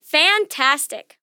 Voices Expressions Demo